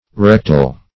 Rectal \Rec"tal\ (r?k"tal), a. (Anat.)